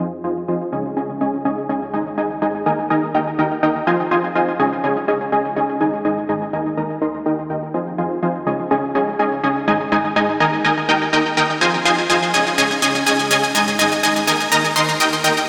Nexus-例-フィルターアクション.mp3